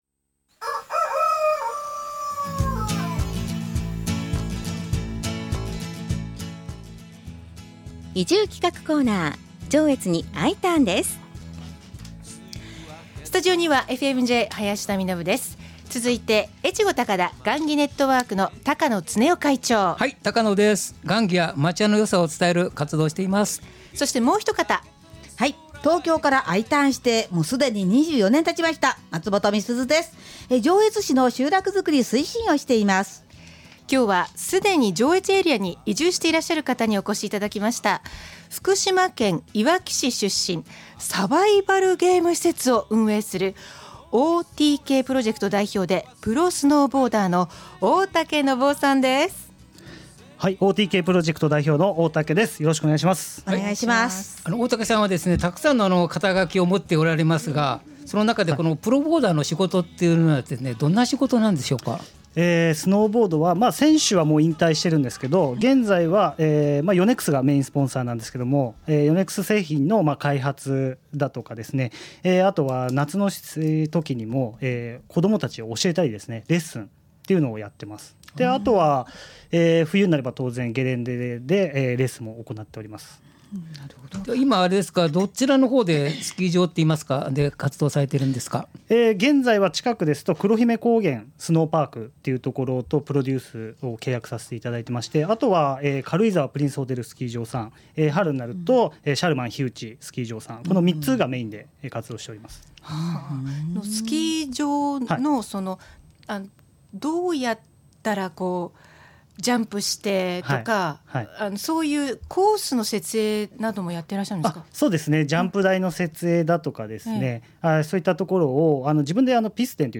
FM-Jのスタジオから移住をお誘いするコーナー。